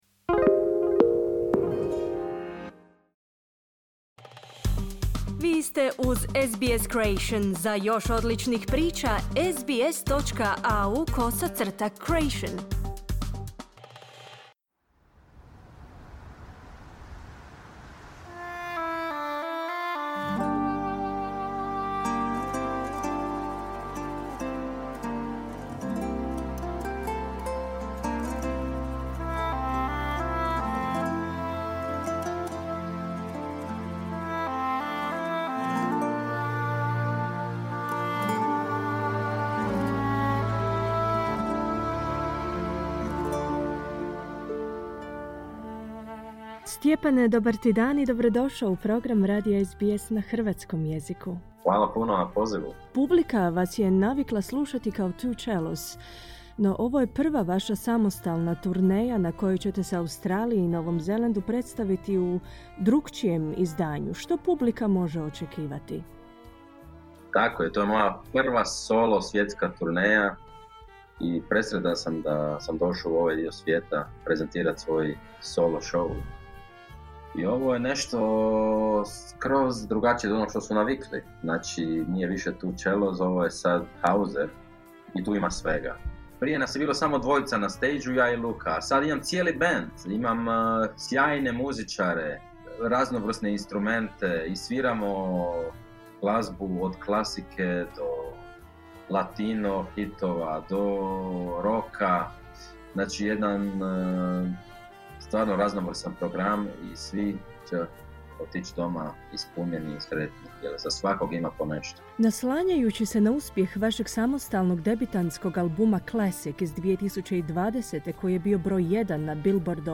U razgovoru